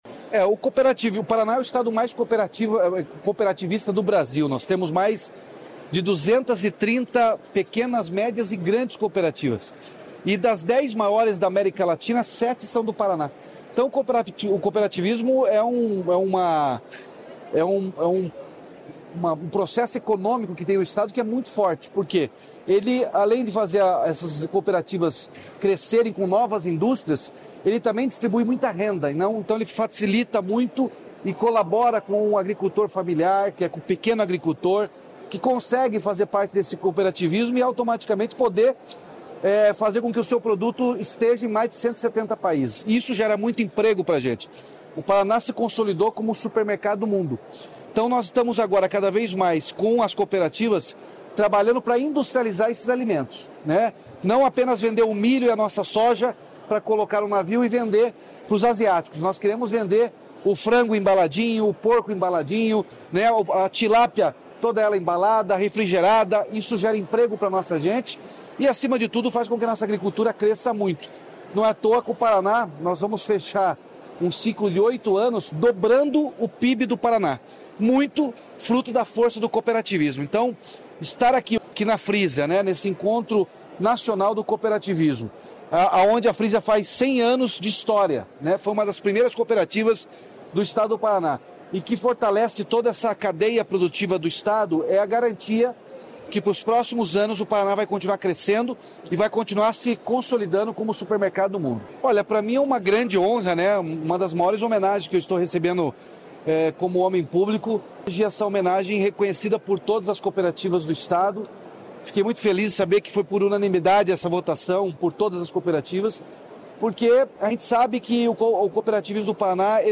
Sonora do governador Ratinho Junior sobre Troféu Ocepar por contribuição ao cooperativismo paranaense